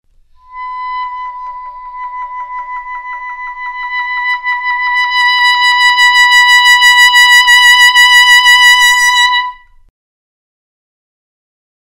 Another example of vibrato depth, which is meant to expand a microtonal trill, is found in an etude by the author (Example #57).
A spectrogram displays the increasing strength of pulses just above the fundamental, a strong 2 nd partial throughout, and a frequency band of increasing strength and size from the 3 rd partial higher through 20,000 hz.
Microtonal trill to vibrato (0-7 seconds)
Microtonal trill to vibrato (7-14 seconds)